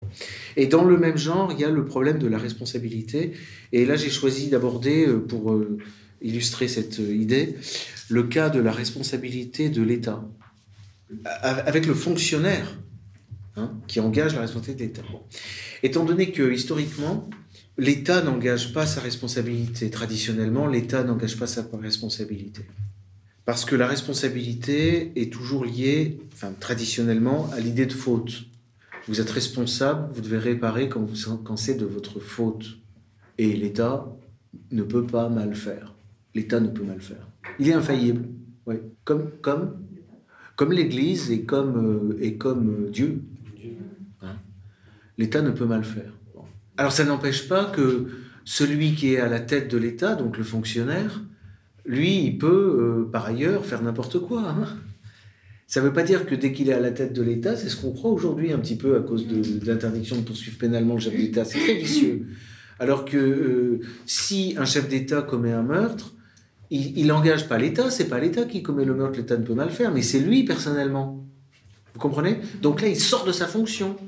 Le séminaire « La faute du fonctionnaire » dure une heure, c’est le live d’un cours de droit que j’ai délivré dans le cadre des Formations d’Egalité et Réconciliation.